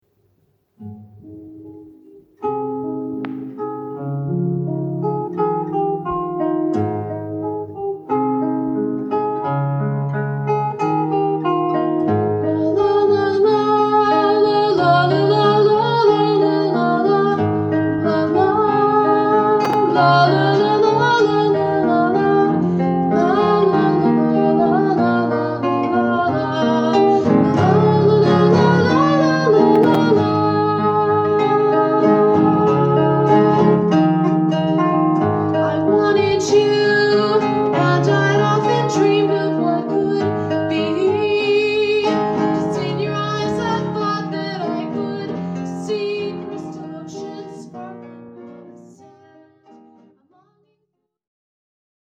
crystal-oceans-casssette-clip.mp3